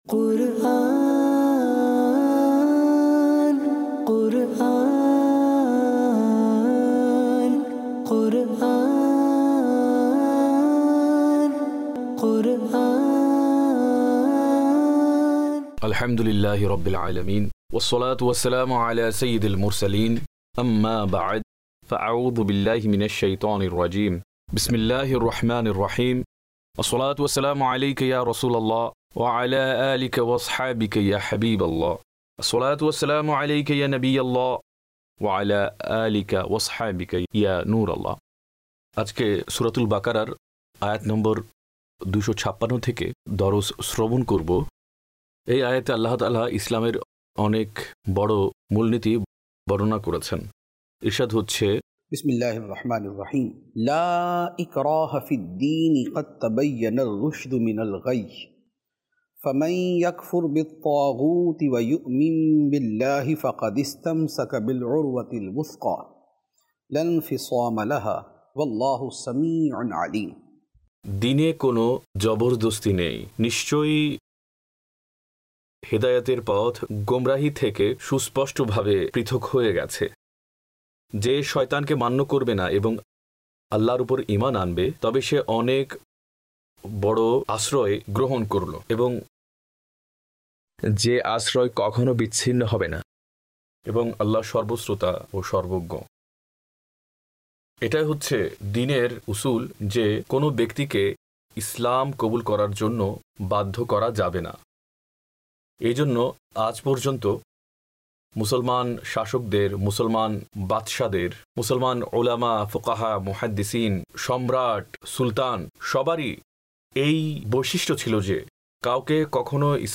সহজ দরসে কুরআন (বাংলায় ডাবিংকৃত) EP# 16